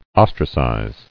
[os·tra·cize]